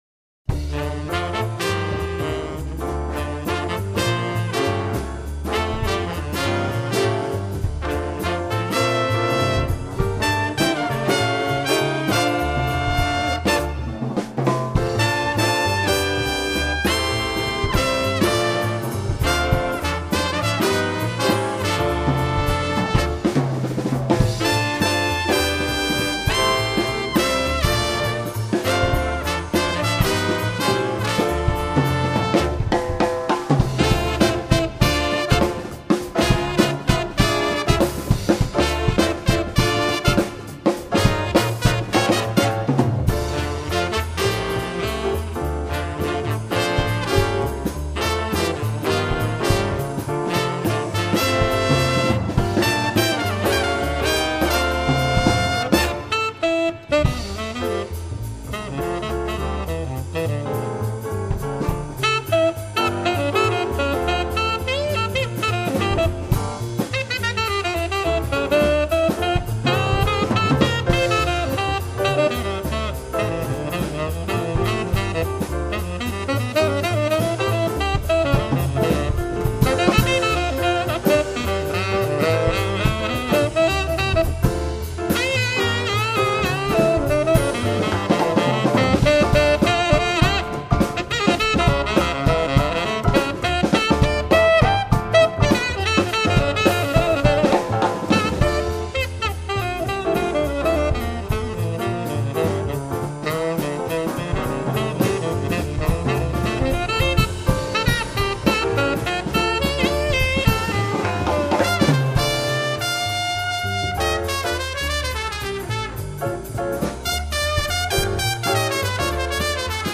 爵士
主奏乐器：钢琴
融合Fusion、Latin Jazz、Swing等多元风格，惊人指技快速拨滑点击的现代爵士新风貌。